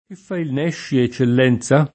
ke ffa il n$šši, e©©ell$nZa?]